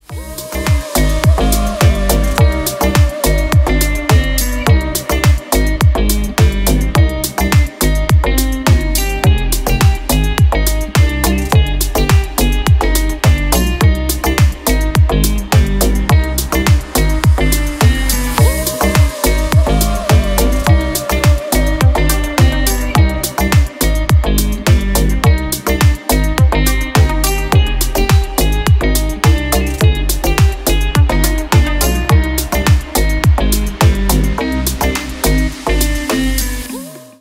deep house
грустные
инструментал
печальные